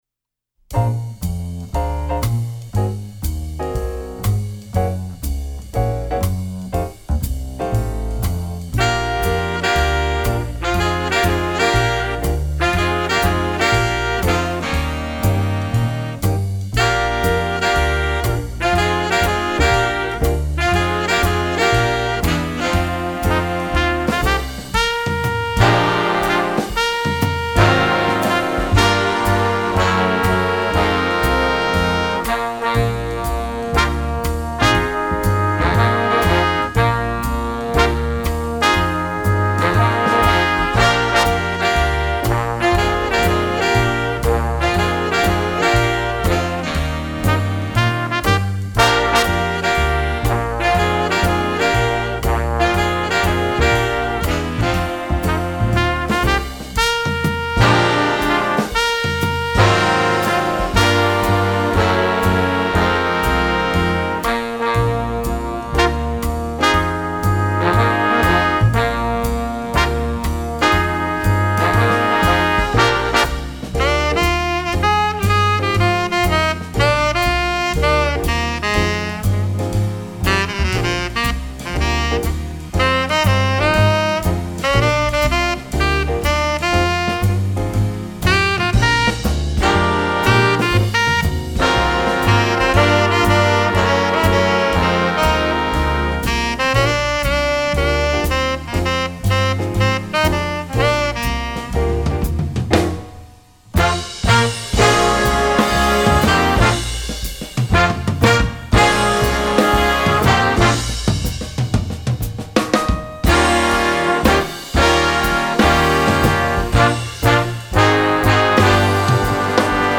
jazz, instructional